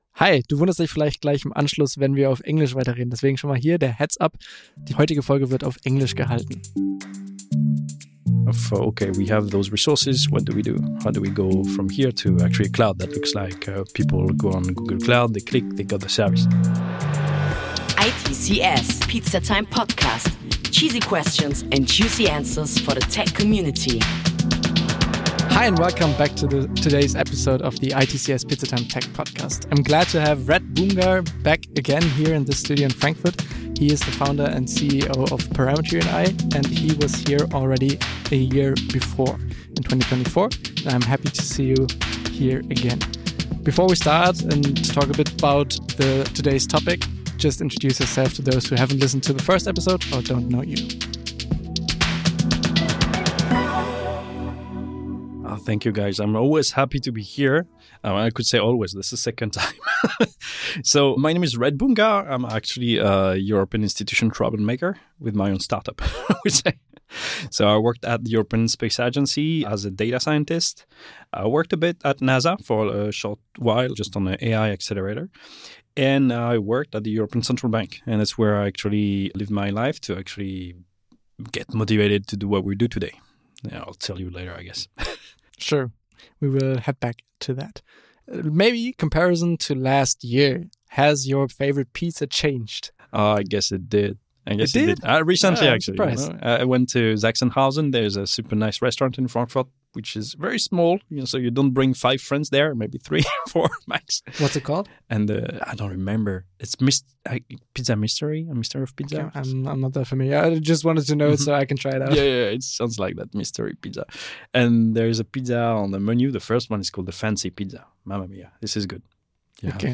Seid dabei und lasst Euch inspirieren, wenn wir Brancheninsider aus den verschiedensten Bereichen zu den aktuellsten und spannendsten Tech- und IT-Trends befragen. Wir liefern Euch Tech-Talks, die auch mal über den Tellerrand hinaus schauen dürfen, das Ganze natürlich frei aufs Haus!